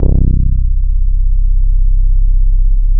95 BASS   -R.wav